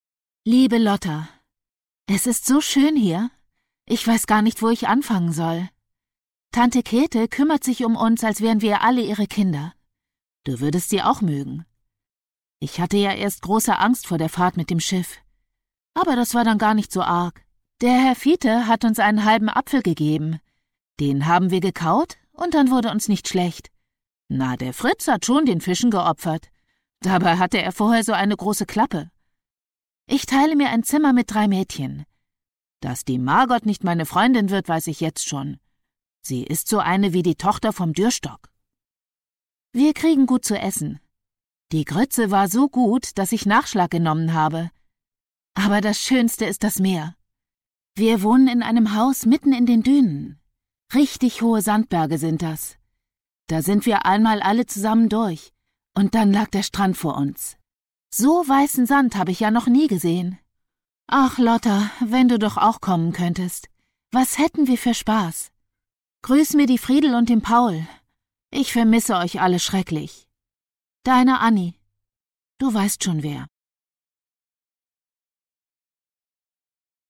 Produktionsart: ungekürzt